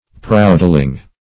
Proudling \Proud"ling\, n. A proud or haughty person.